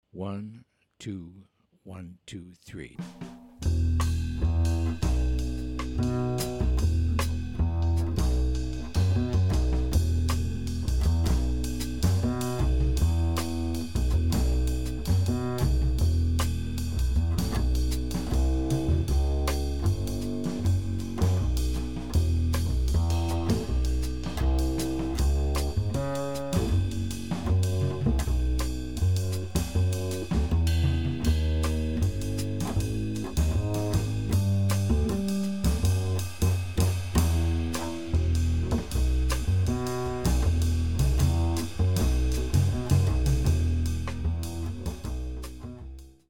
A beautiful bossa with an extended AABC form.
minus Piano